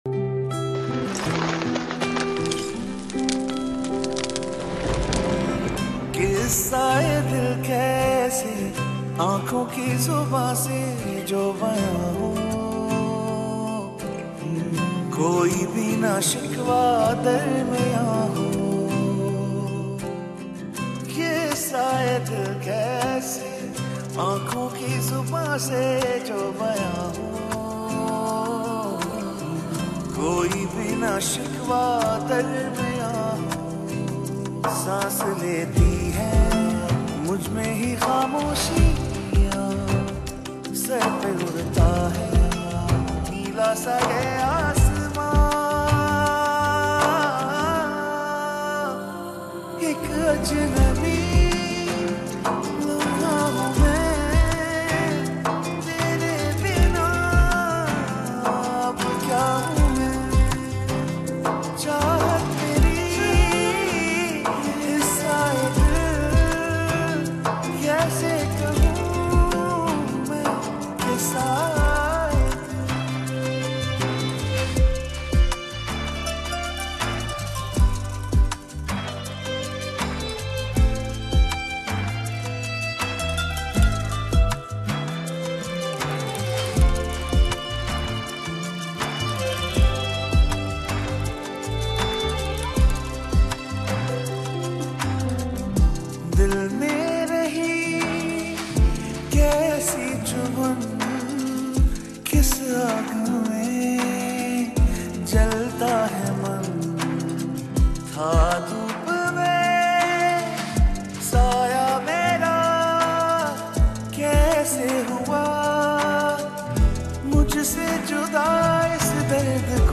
Pakistani Dramas OST